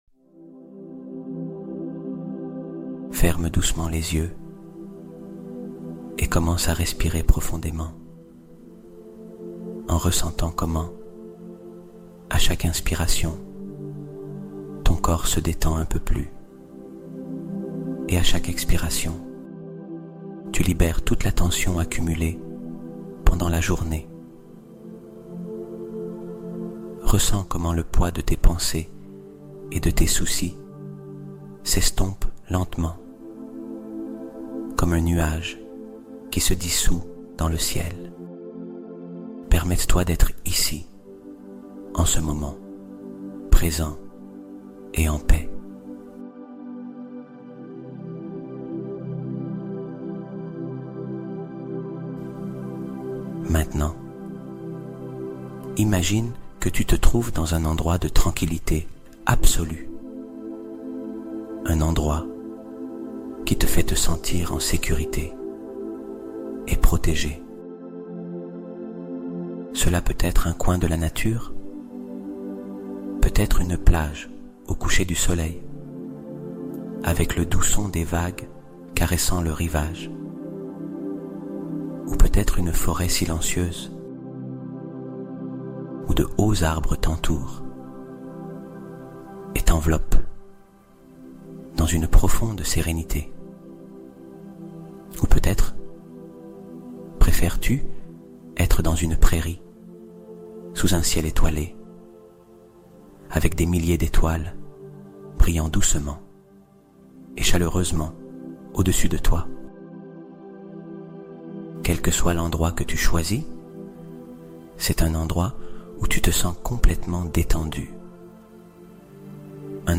Reprogrammation du Destin : Méditation nocturne pour un futur harmonieux